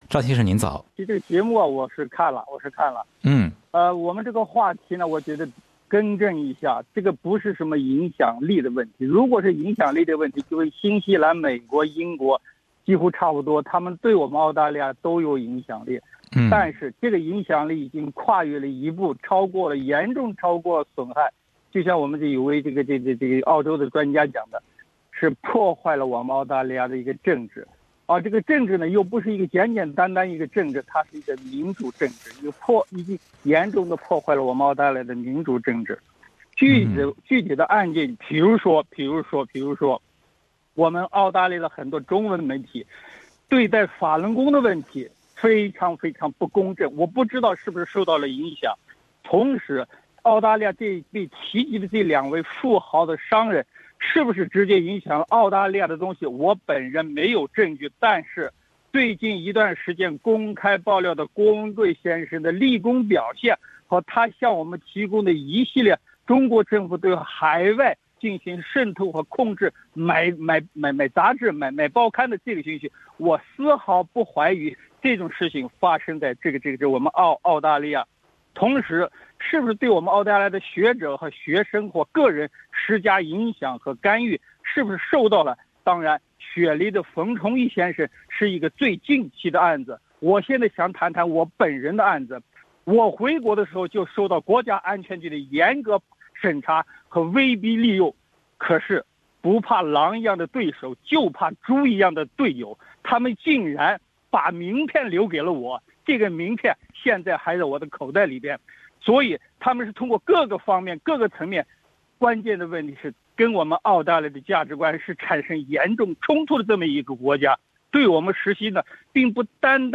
本台正在行动节目的一些听众在热线中表达了自己的观点，认为中国的影响力已经严重破坏了澳洲的民主政治。